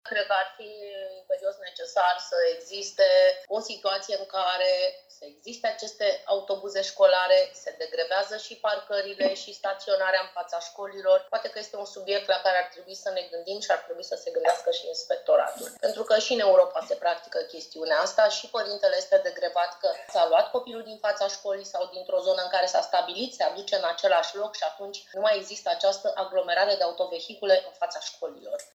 Desfășurată în format online, ședința de azi a Consiliului Local Constanța a avut pe ordinea de zi 3 proiecte de hotărâre.